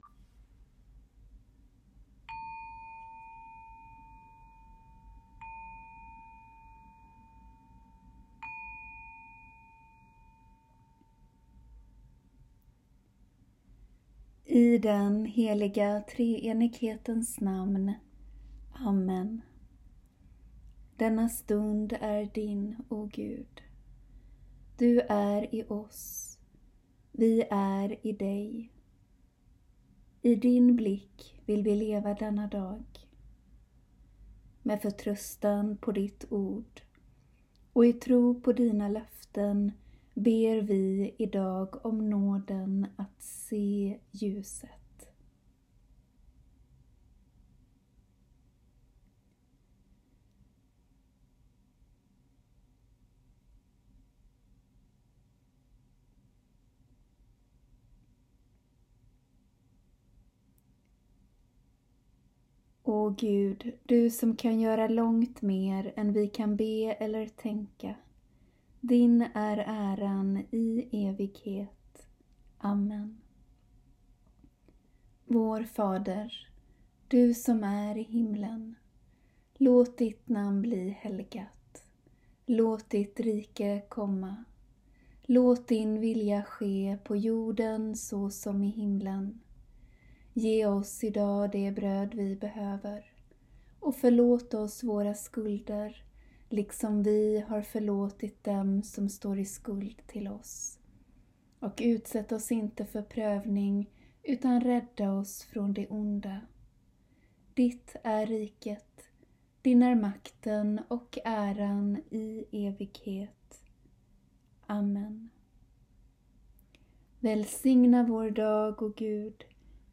Bön vid dagens början (2 min)